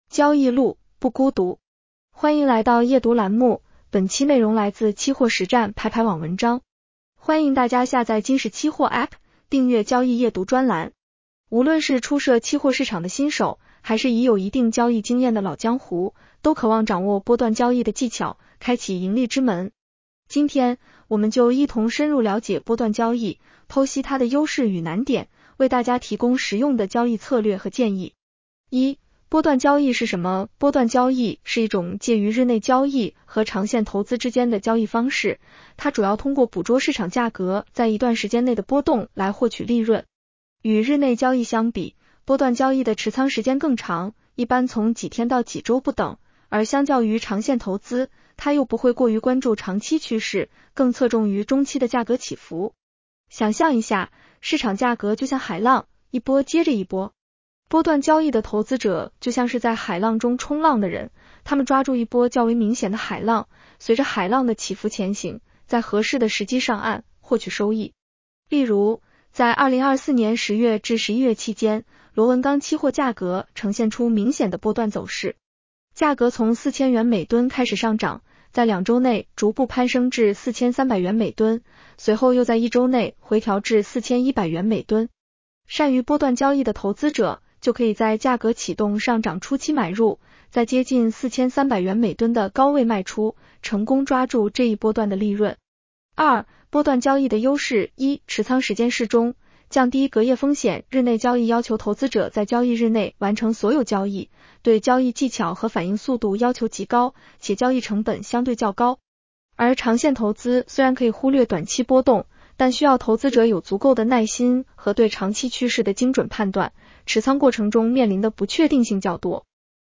女声普通话版 下载mp3 无论是初涉期货市场的新手，还是已有一定交易经验的 “老江湖”，都渴望掌握波段交易的技巧，开启盈利之门。